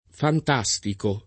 vai all'elenco alfabetico delle voci ingrandisci il carattere 100% rimpicciolisci il carattere stampa invia tramite posta elettronica codividi su Facebook fantastico [ fant #S tiko ] agg.; pl. m. ‑ci — sim. il cogn.